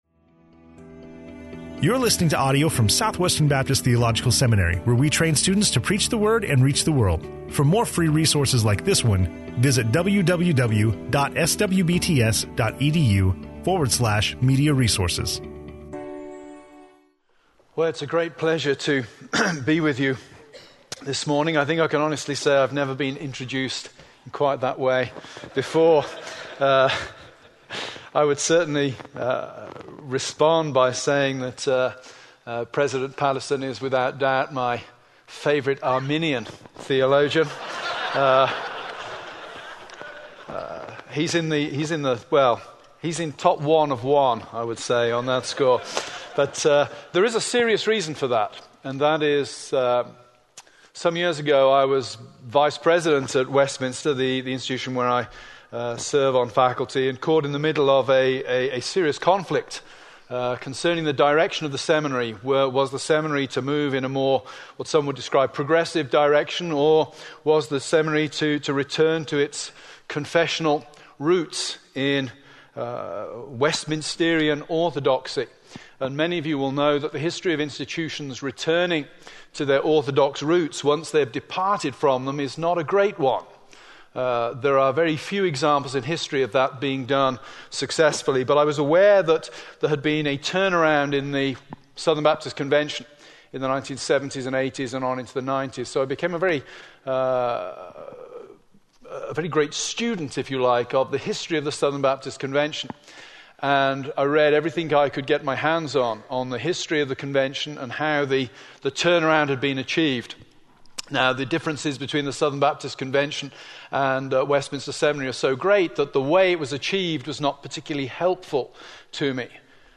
Dr. Carl Trueman speaking on I Kings 17:1-24 in SWBTS Chapel on Thursday October 9, 2014
SWBTS Chapel Sermons Carl Trueman - The Advent of the Prophet Elijah Play Episode Pause Episode Mute/Unmute Episode Rewind 10 Seconds 1x Fast Forward 30 seconds 00:00 / Subscribe Share RSS Feed Share Link Embed